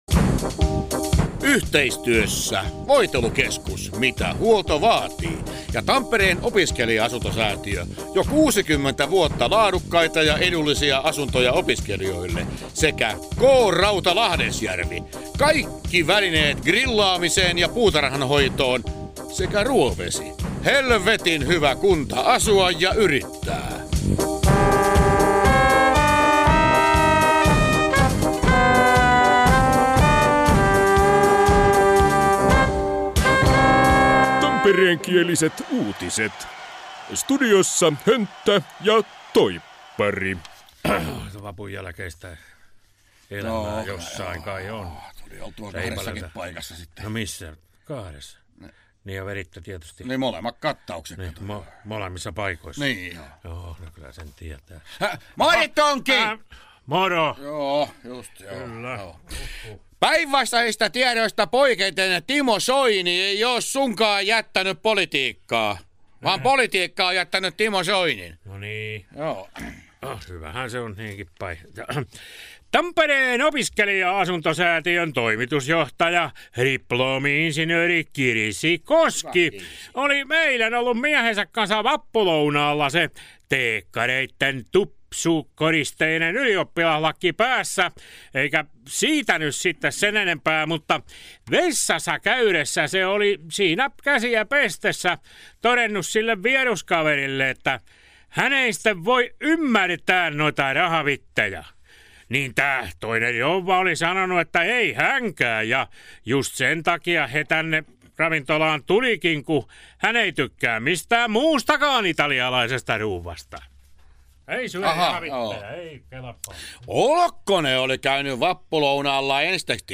Tampereenkiäliset uutiset